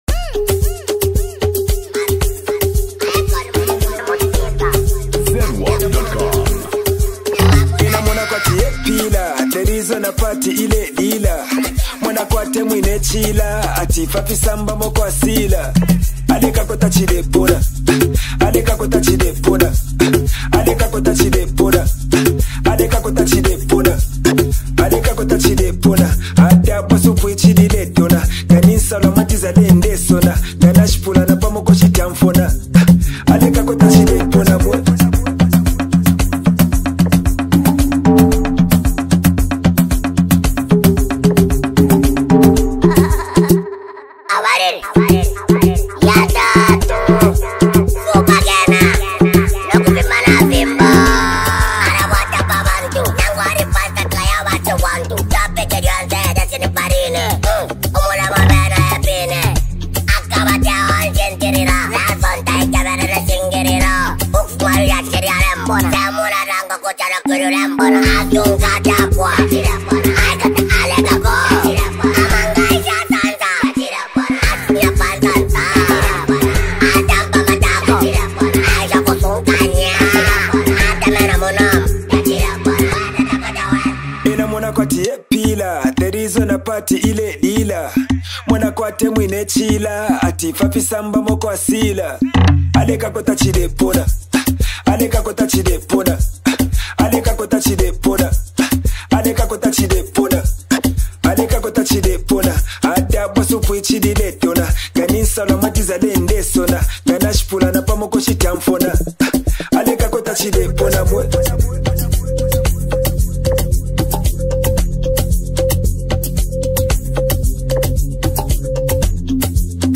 a high-energy track
smooth yet impactful rap style